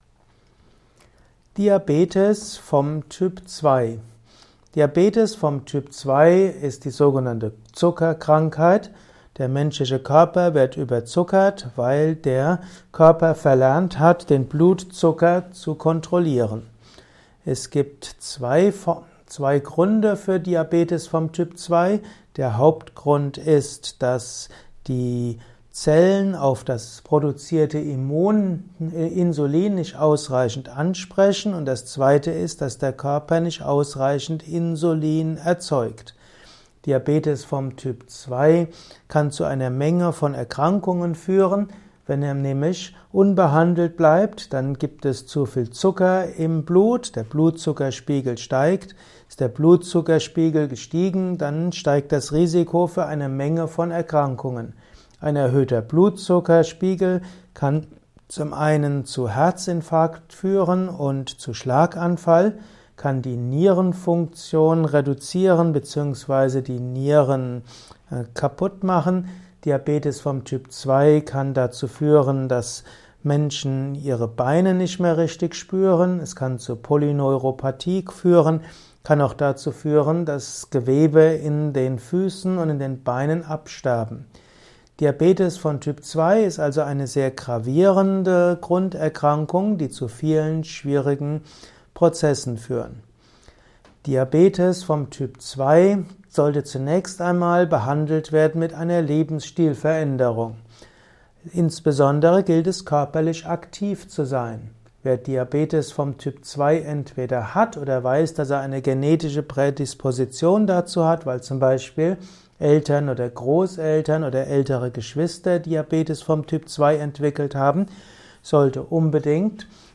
Ein Kurzvortrag über die Krankheit Diabetis Typ 2